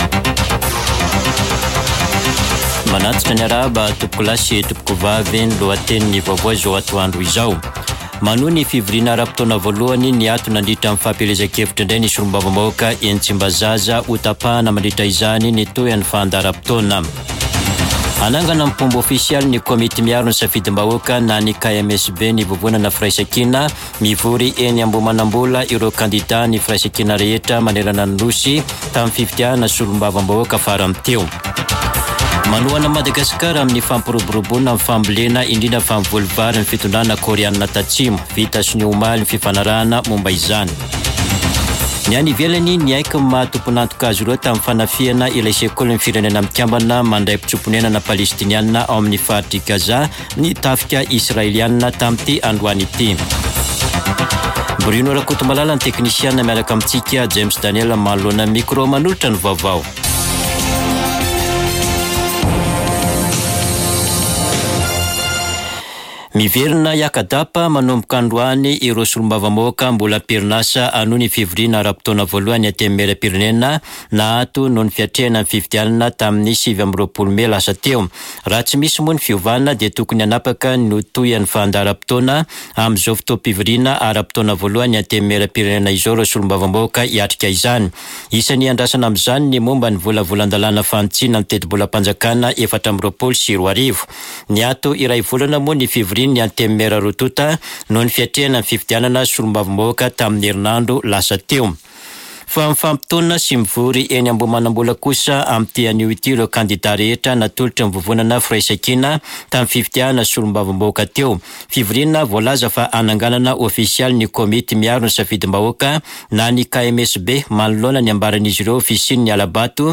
[Vaovao antoandro] Alakamisy 06 jona 2024